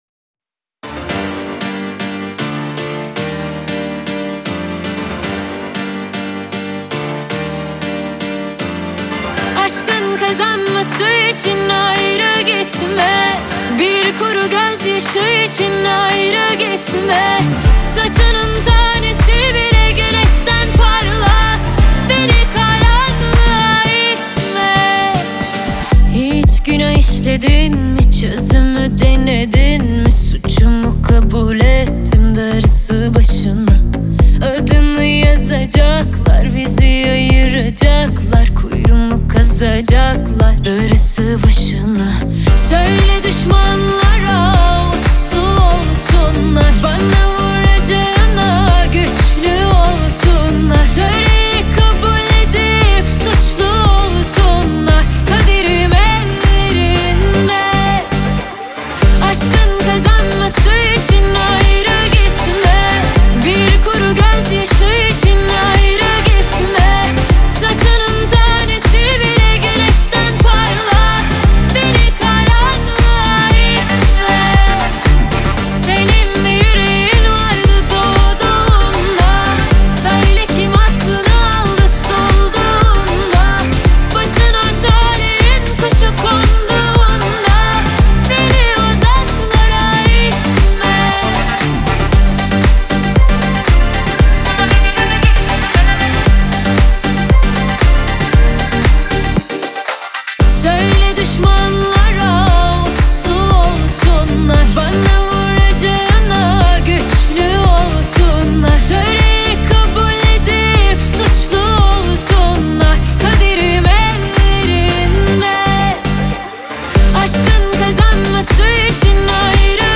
Трек размещён в разделе Турецкая музыка.